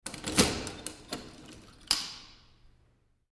В подъезде вскрыли почтовый ящик ключом